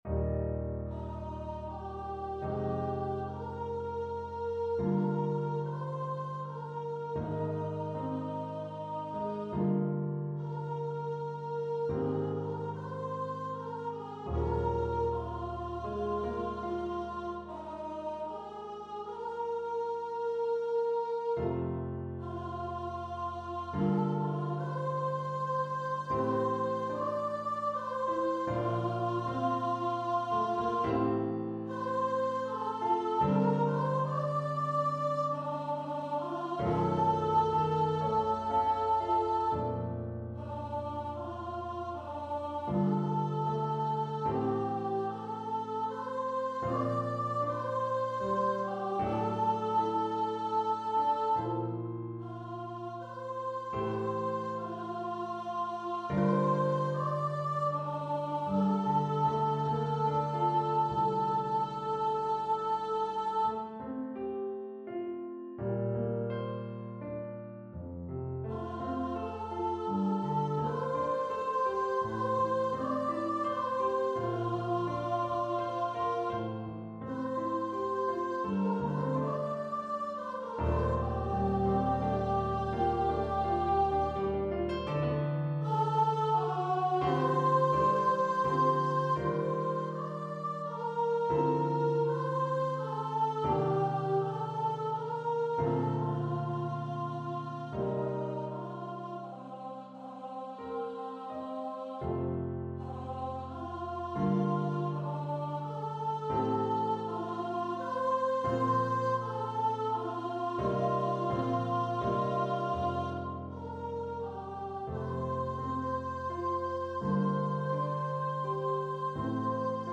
for voice and piano
It is suitable for male or female voice.